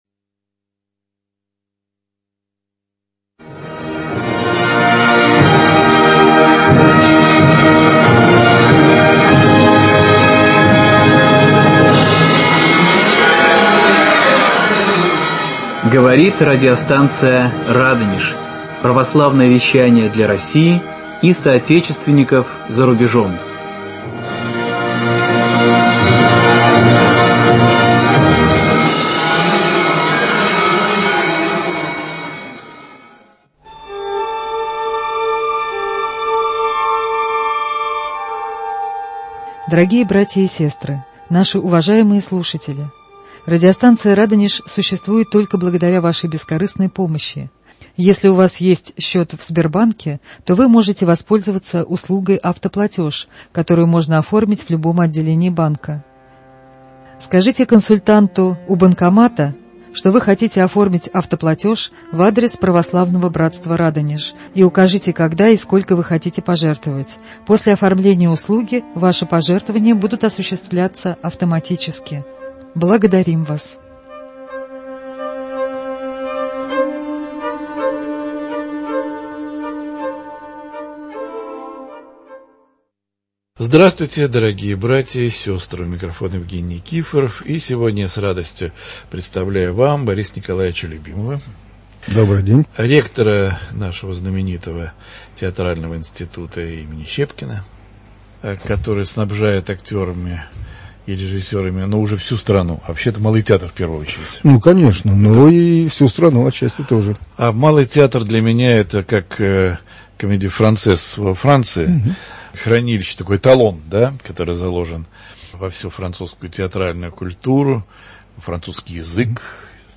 Борис Николаевич Любимов, ректор Театрального училища имени Щепкина вспоминает о своем пути к Богу и церковной жизни Москвы в 60-е, 70-е и 80-е годы.